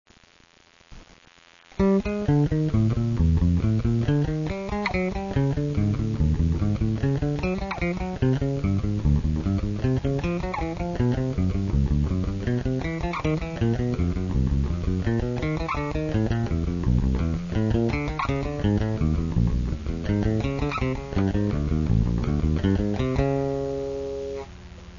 rythme à appliquer:  croche ou double croche avec un tempo donné par le métronome